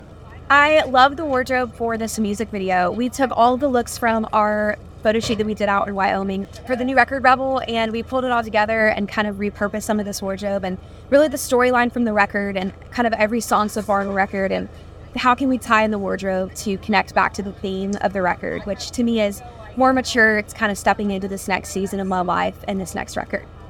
Anne Wilson talks about the impact of the wardrobe in her new music video for "Rain In The Rearview."